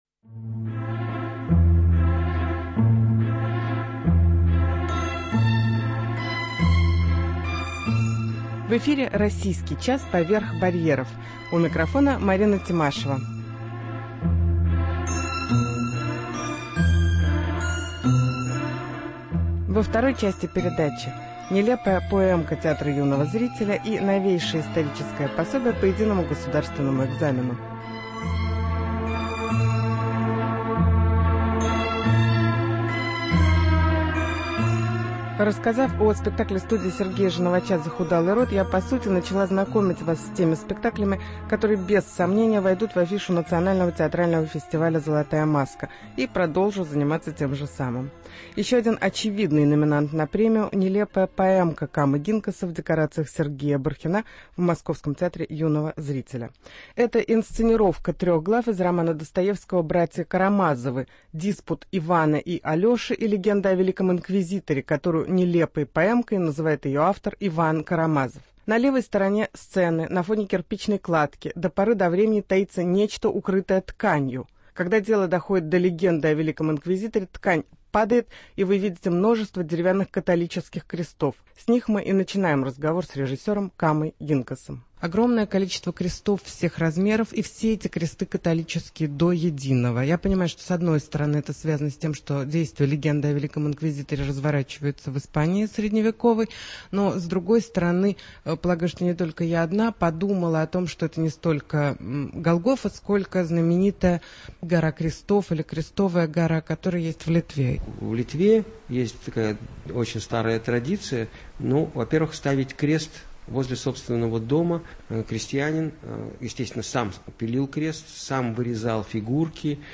Спектакль "Нелепая поэмка" и интервью с режиссером Камой Гинкасом